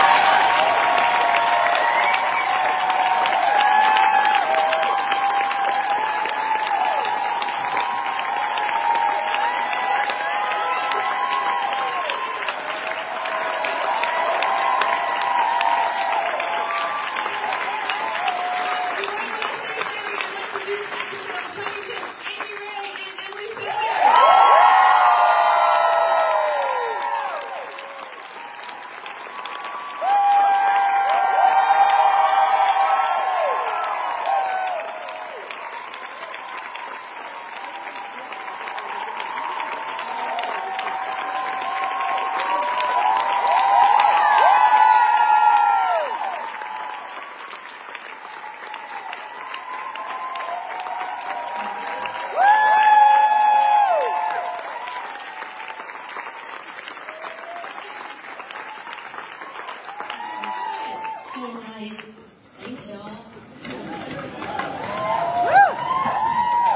01. introduction (1:06)